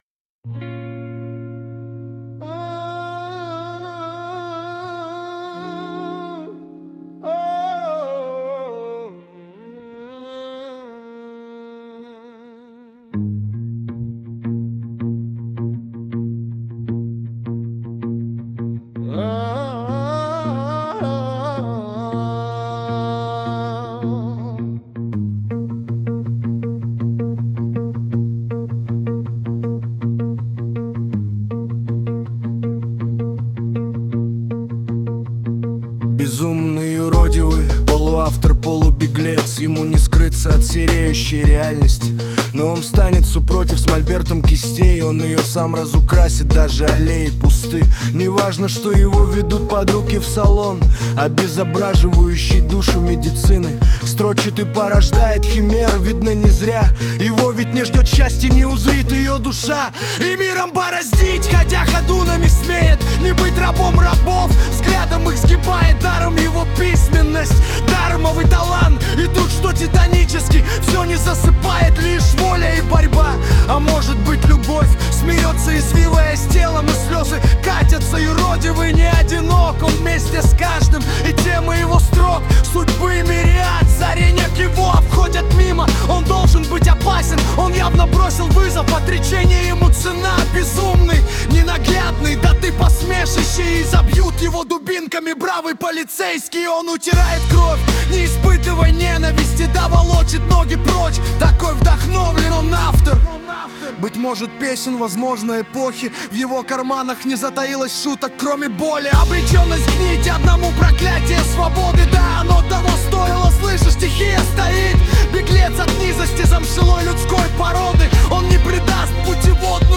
Музыка и исполнение принадлежит ИИ.
ТИП: Пісня
СТИЛЬОВІ ЖАНРИ: Драматичний